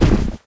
drop_1.wav